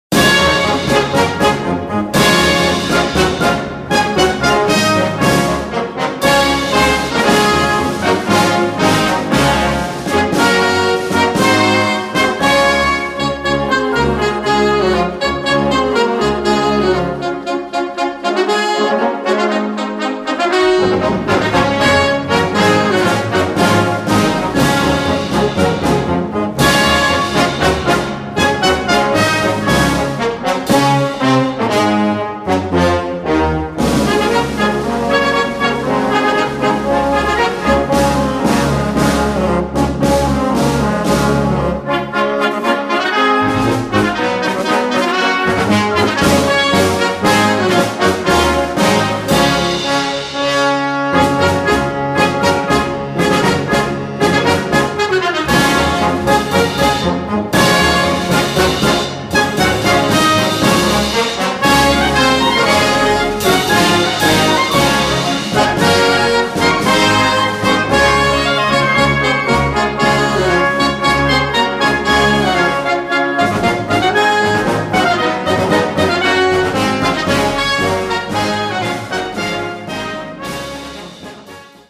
Gattung: Concert Band
Besetzung: Blasorchester